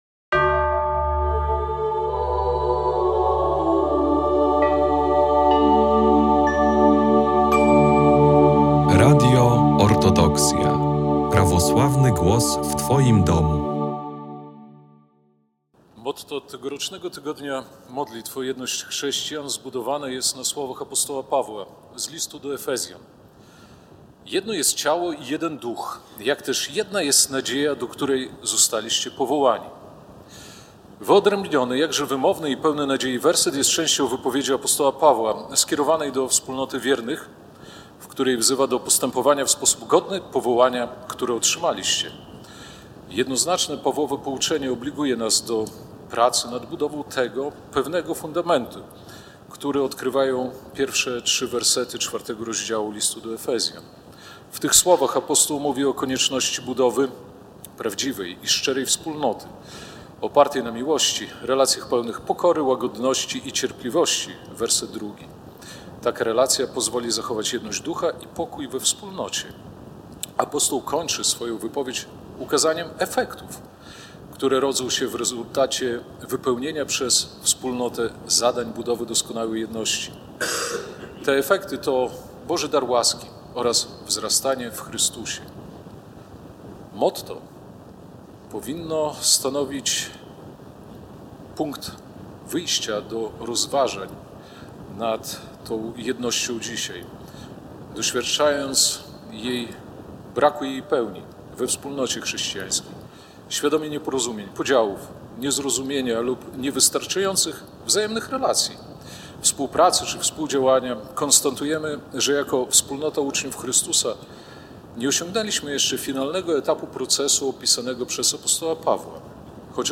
Relacja z nabożeństwa w ramach Tygodnia Modlitw o Jedność Chrześcijan
19 stycznia w cerkwi Zmartwychwstania Pańskiego w Białymstoku Jego Ekscelencja Arcybiskup Białostocki i Gdański Jakub przewodniczył wielkiej wieczerni. Nabożeństwo sprawowane było w ramach Tygodnia Modlitw o Jedność Chrześcijan. fot.